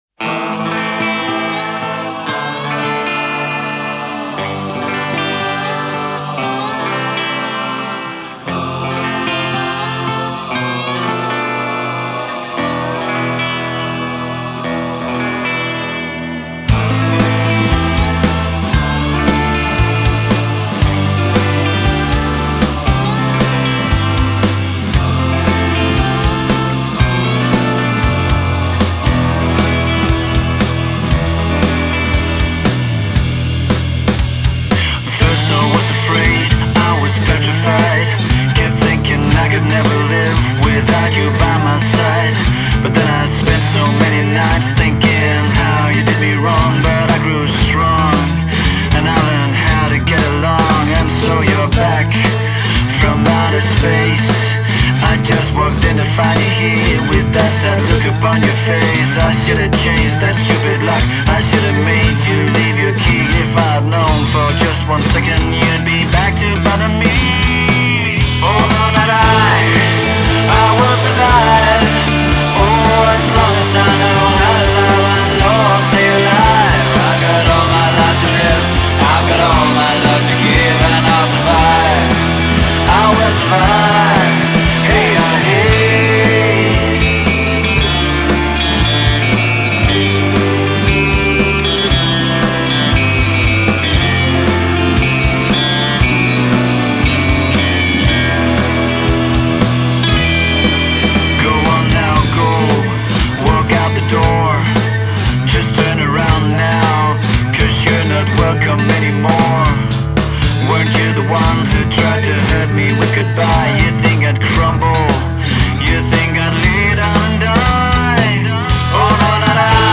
Danish trio